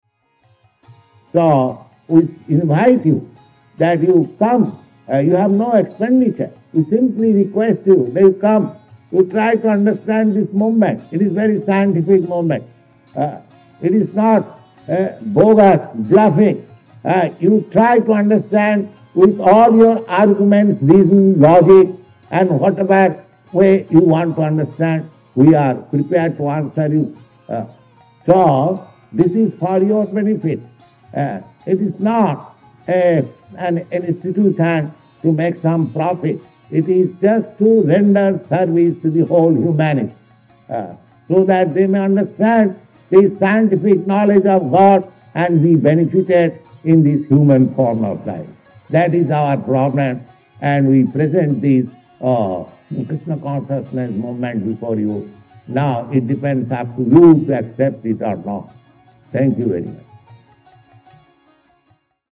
(680510 - Lecture at Boston College - Boston)